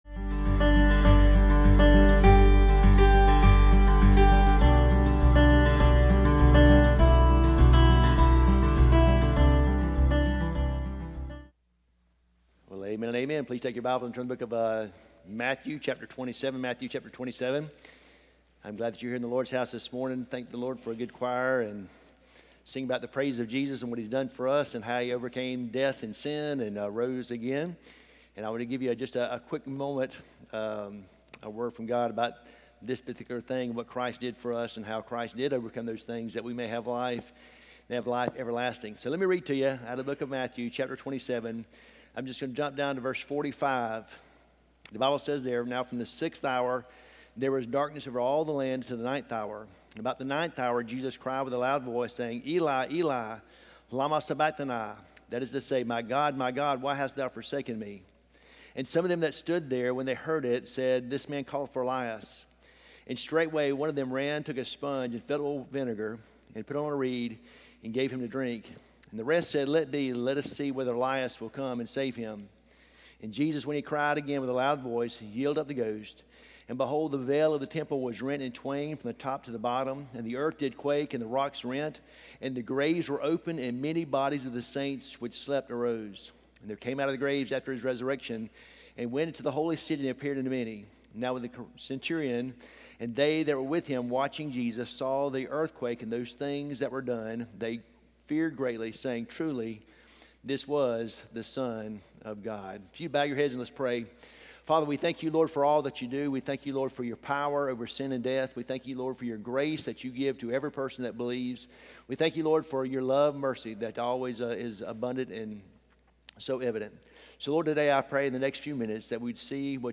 Sermons 2 – Sardis Baptist Church | Worthington Springs, FL